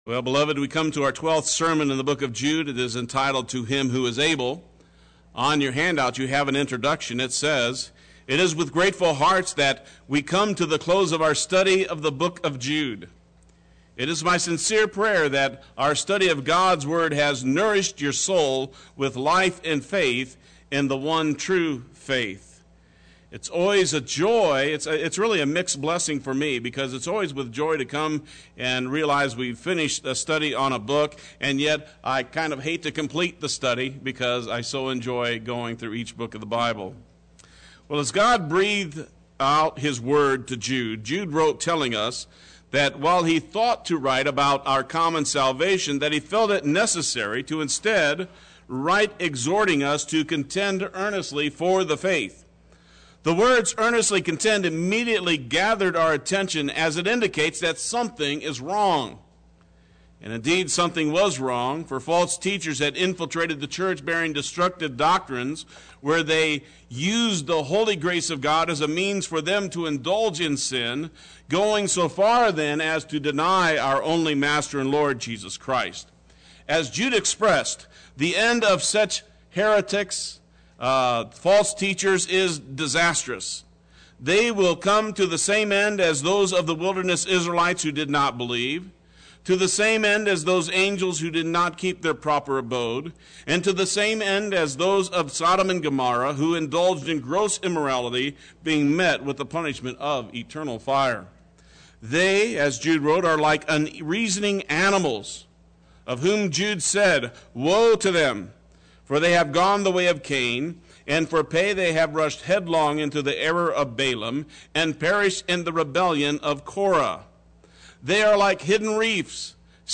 Play Sermon Get HCF Teaching Automatically.
To Him Who is Able Sunday Worship